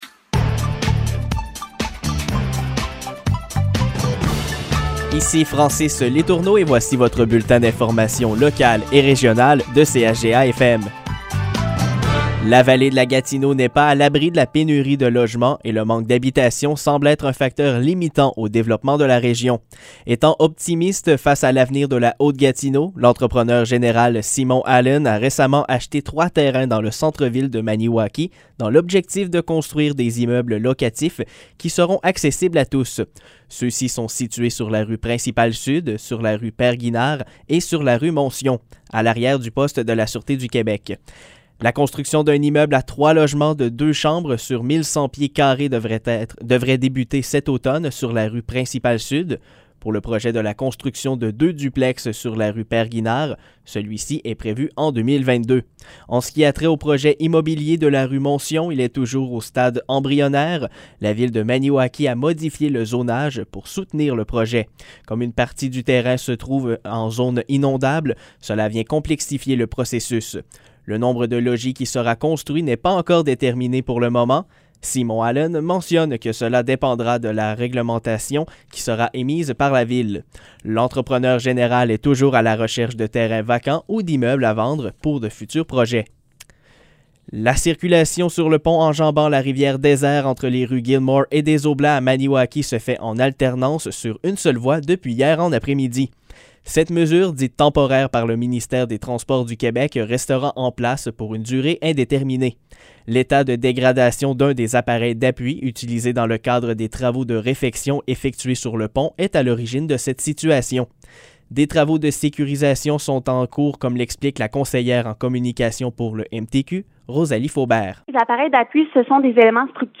Nouvelles locales - 20 octobre 2021 - 15 h